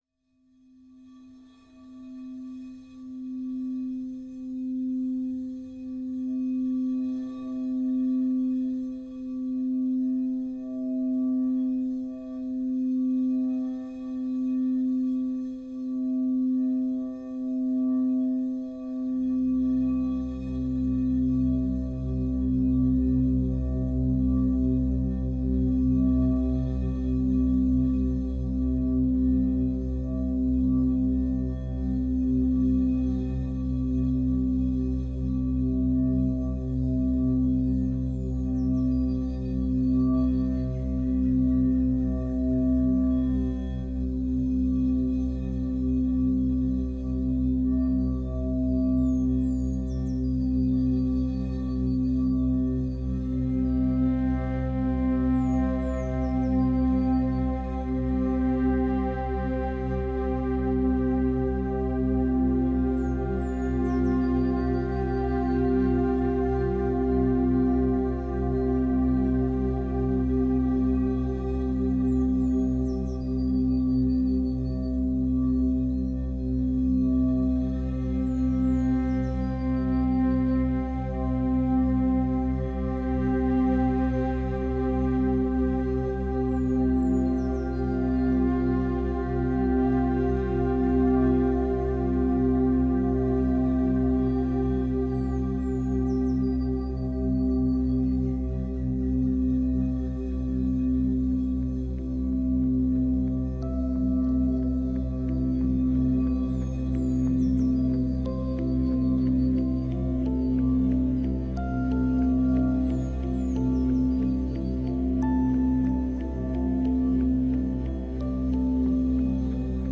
No vocals in this...I like where it went :3 I am officially back in Splice land ~ but did you notice the Music Nerd hat tho !?